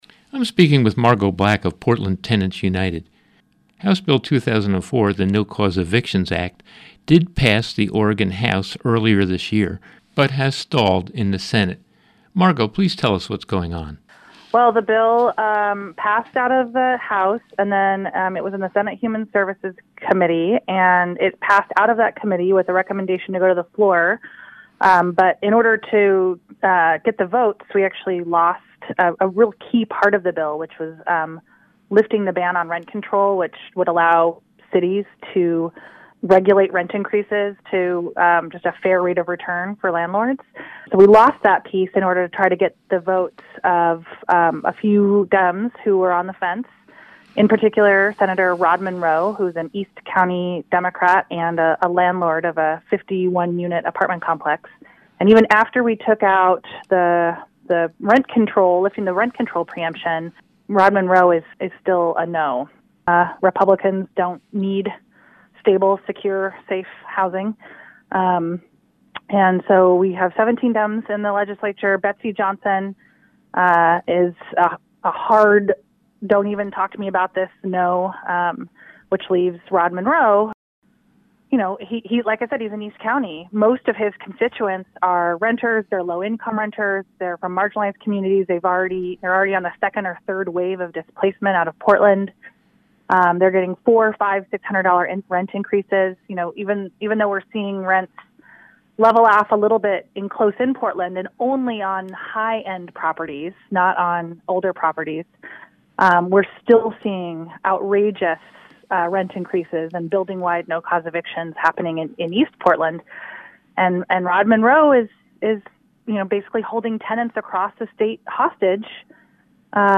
KBOO News Team